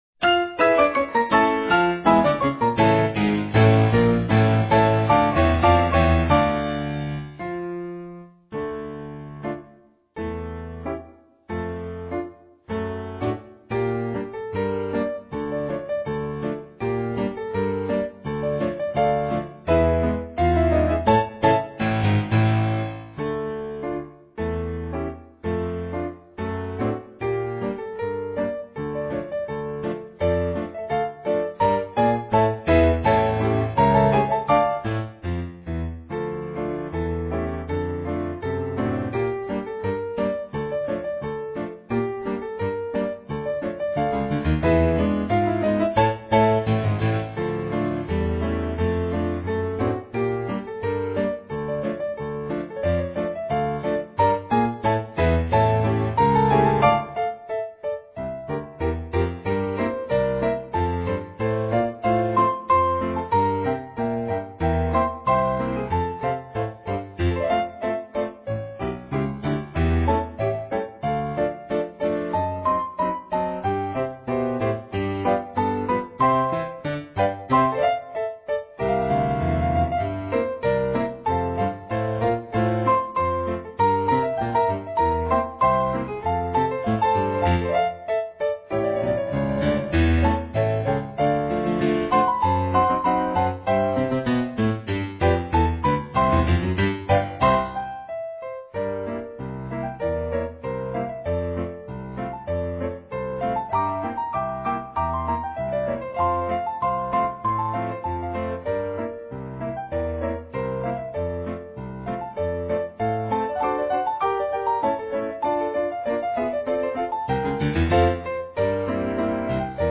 The trains begin their run towards each other just after three quarters of the way into the piece, followed quickly by the crash.